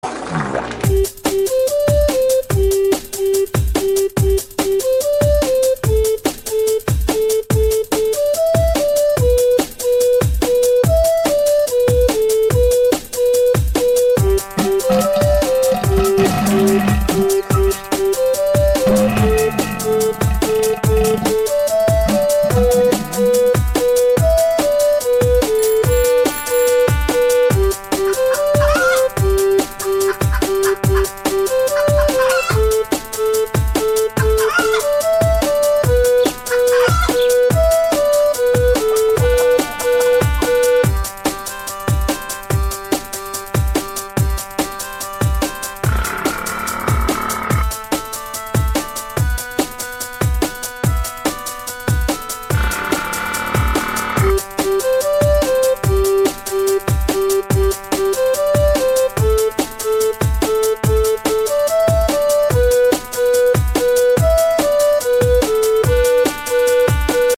mainmusic.mp3